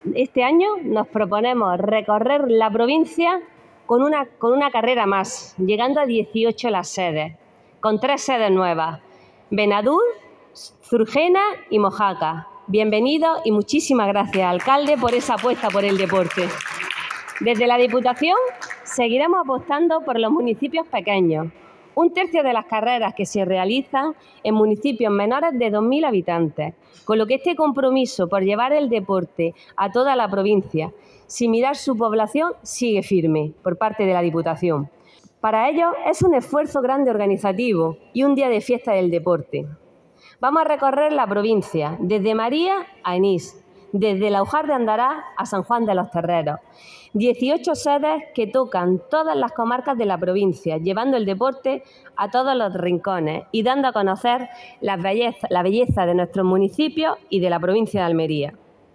En un acto celebrado con la presencia de alcaldes y concejales de los 18 municipios sede, así como corredores y patrocinadores; la diputada de Deporte, Vida Saludable y Juventud, María Luisa Cruz, ha destacado la consolidación de este evento que hunde sus raíces en el antiguo circuito del Levante de 1998.